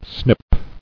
[snip]